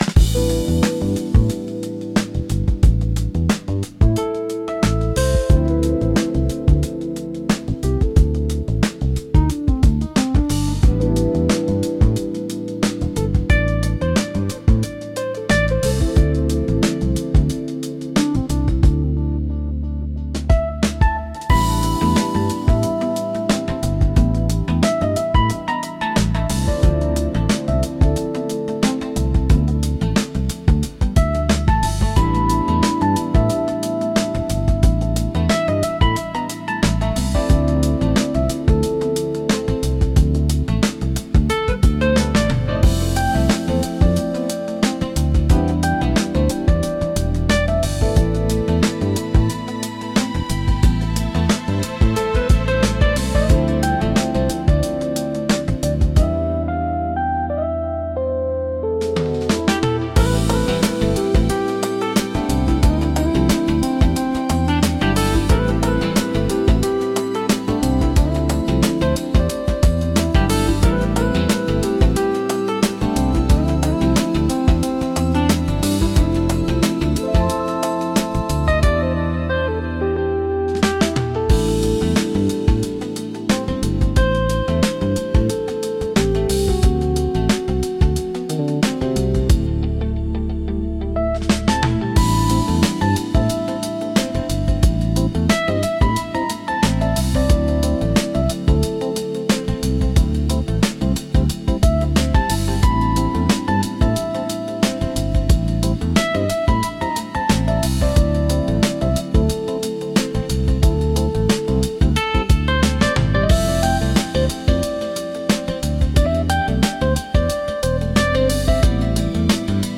リラックス効果が高く、会話の邪魔をせず心地よい背景音として居心地の良さを高めます。